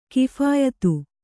♪ kiphāyatu